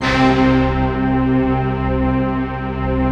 Index of /90_sSampleCDs/Optical Media International - Sonic Images Library/SI1_StaccatoOrch/SI1_Sfz Orchest